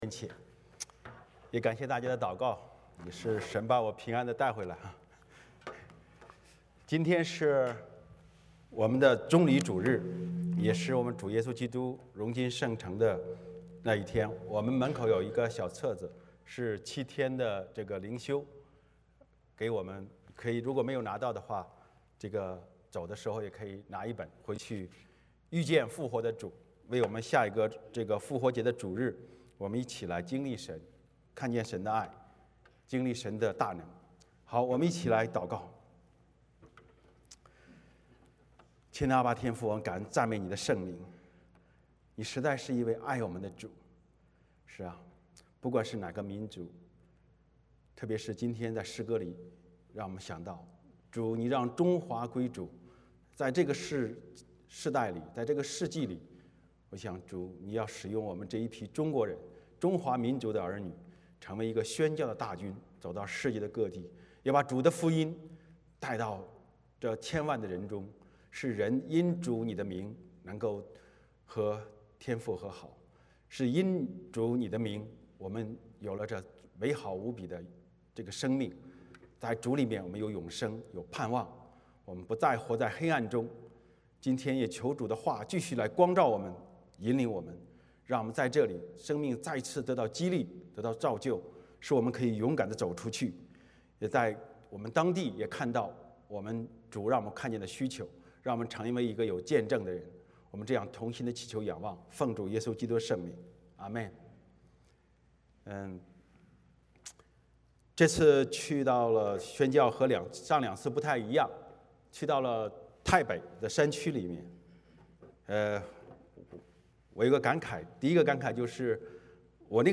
约翰福音 4:34-42 Service Type: 主日崇拜 欢迎大家加入我们的敬拜。